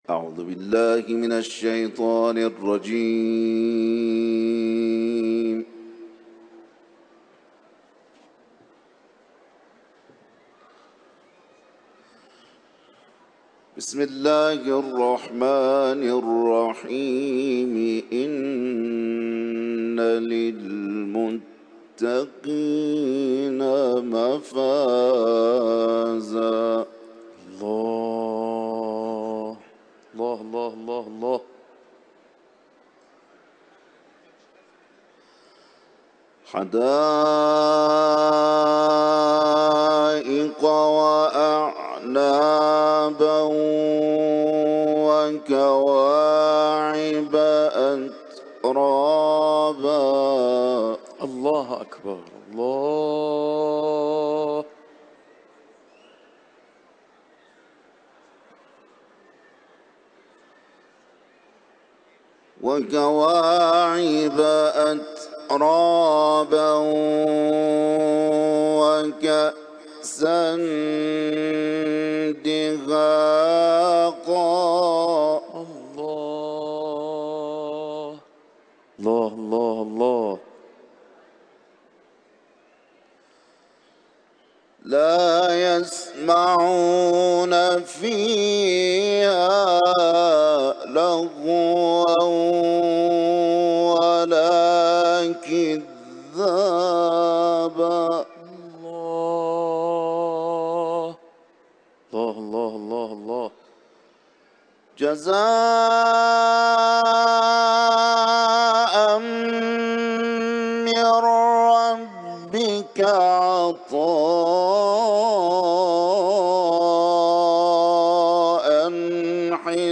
Etiketler: İranlı kâri ، Kuran tilaveti